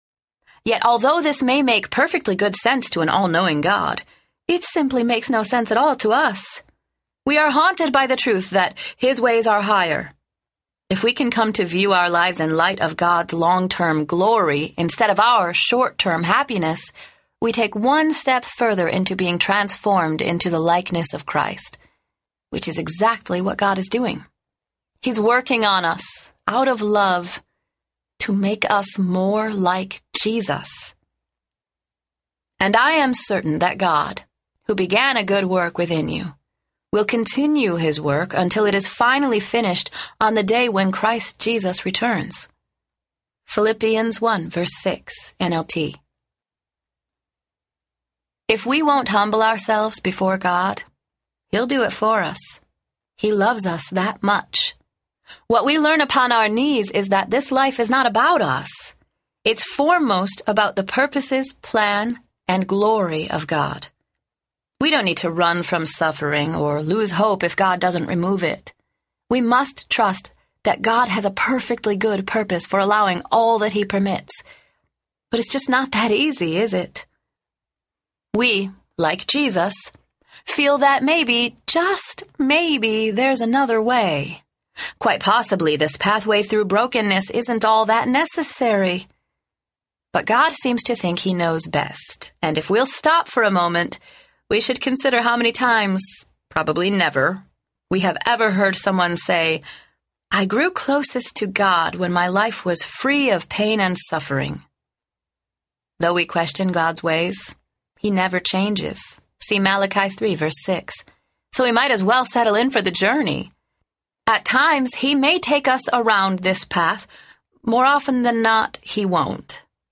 His Love Never Quits Audiobook
Narrator